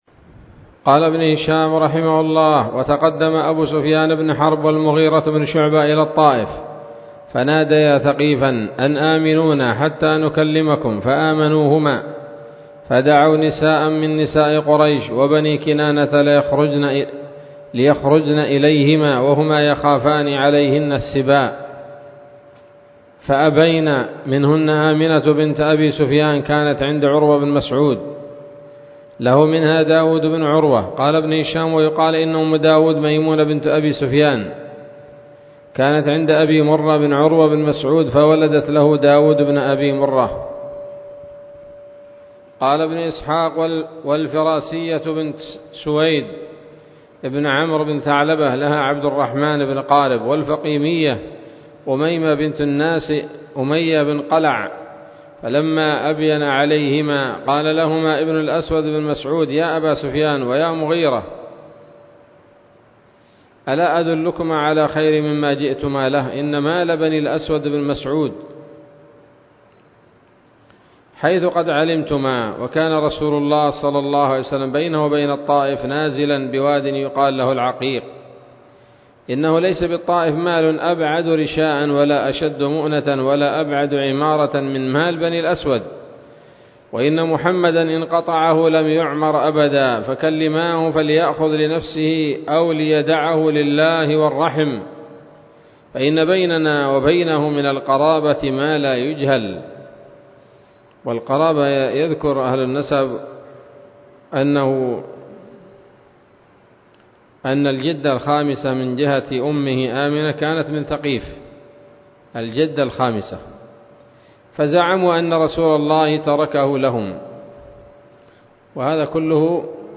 الدرس التاسع والسبعون بعد المائتين من التعليق على كتاب السيرة النبوية لابن هشام